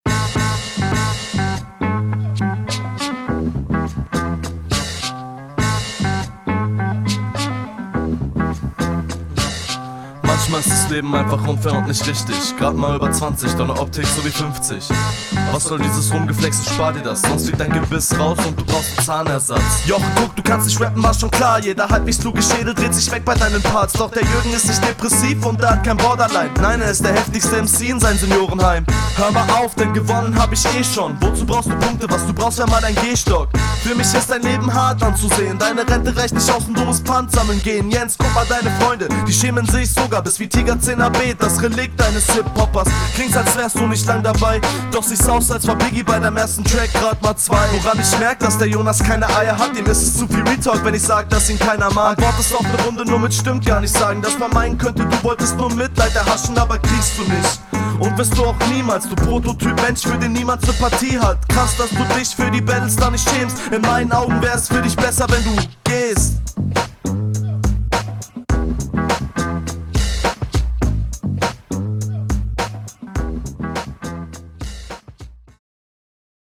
Flow: flow passt zum beat.
Hat definitiv style.